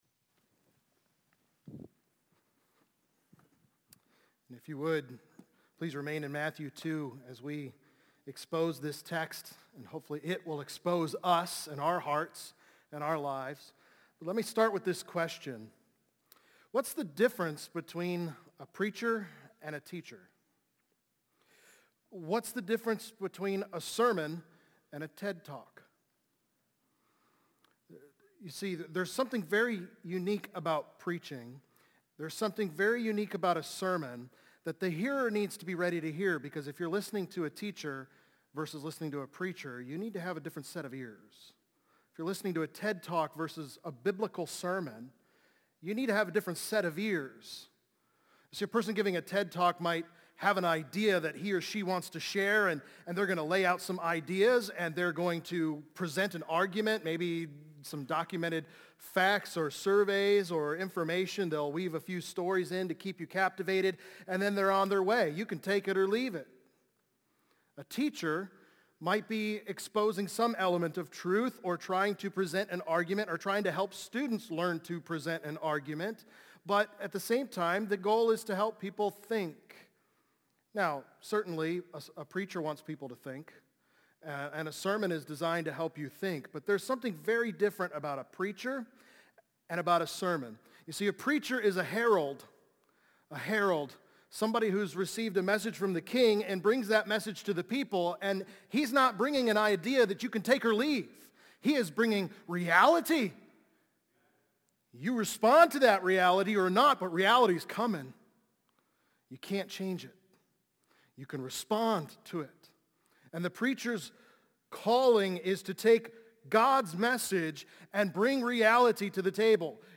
| Baptist Church in Jamestown, Ohio, dedicated to a spirit of unity, prayer, and spiritual growth